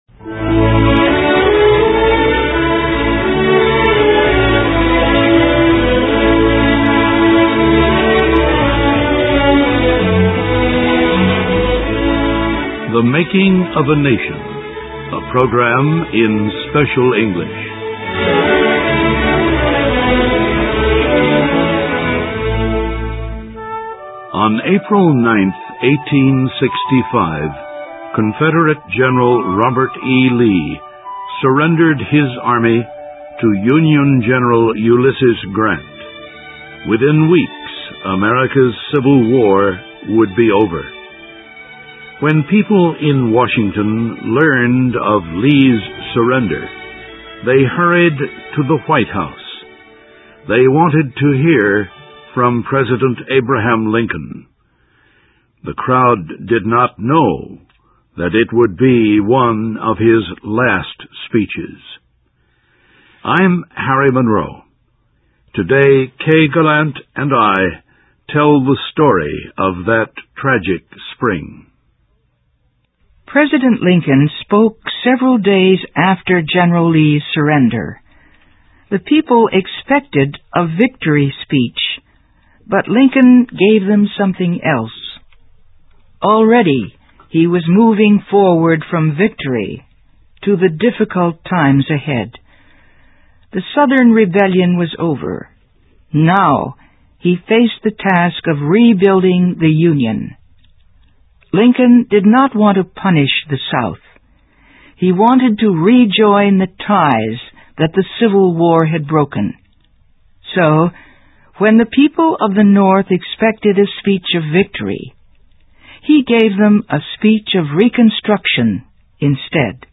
Listen and Read Along - Text with Audio - For ESL Students - For Learning English